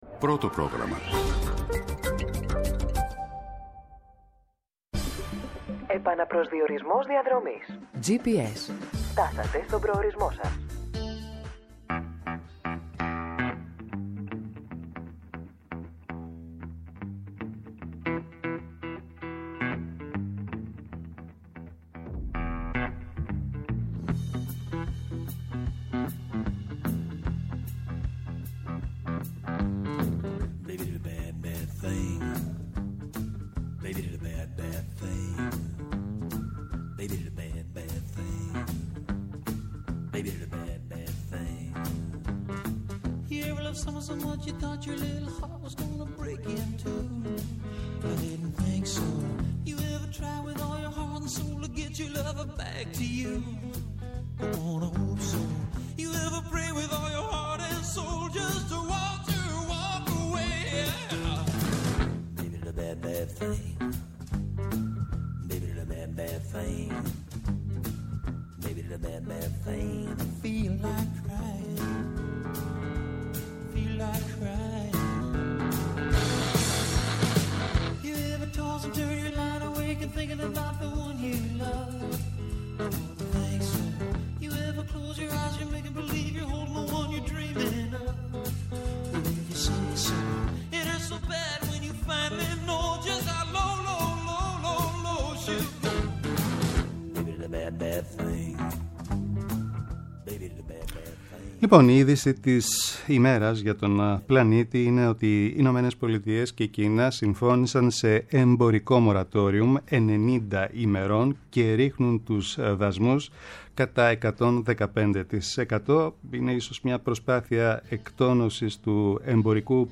-Ο Νίκος Παπαϊωάννου, υφυπουργός Παιδείας, αρμόδιος για την Τριτοβάθμια Εκπαίδευση.
Ένα καθημερινό ραντεβού με τον παλμό της επικαιρότητας, αναδεικνύοντας το κοινωνικό στίγμα της ημέρας και τις αγωνίες των ακροατών μέσα από αποκαλυπτικές συνεντεύξεις και πλούσιο ρεπορτάζ επιχειρεί να δώσει η εκπομπή GPS